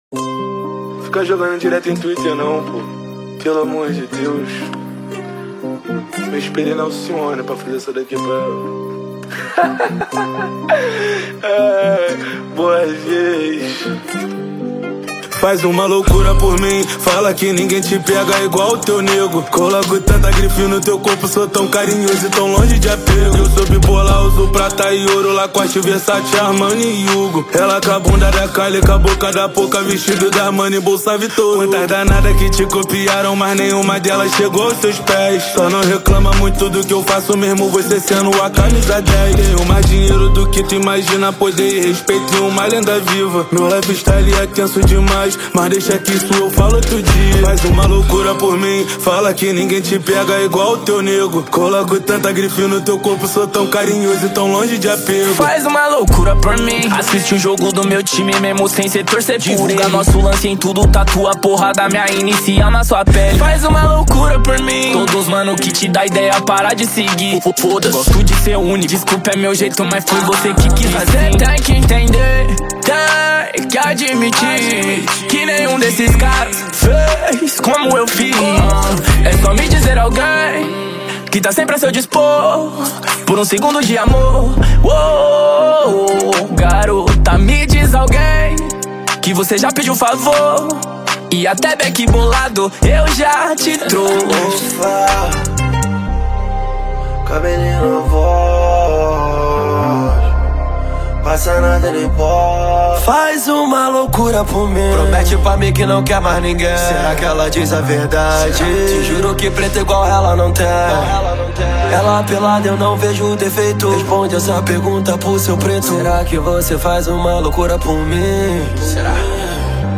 2024-07-25 01:06:03 Gênero: Funk Views